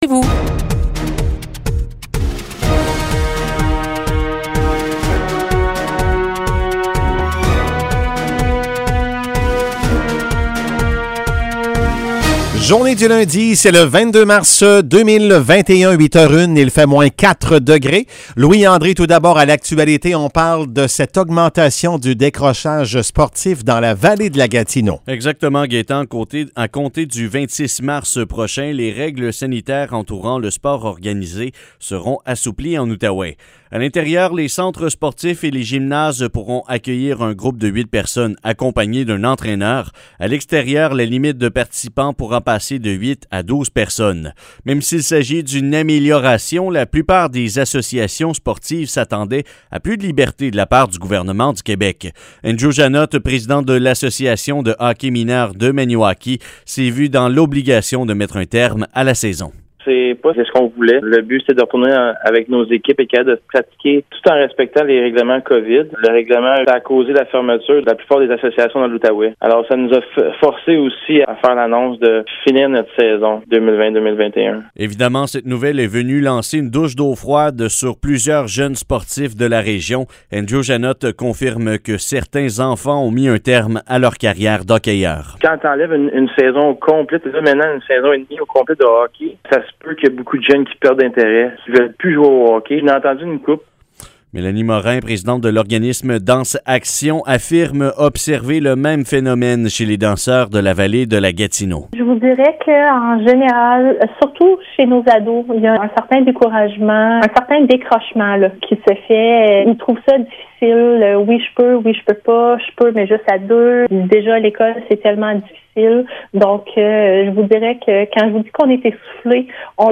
Nouvelles locales - 22 mars 2021 - 8 h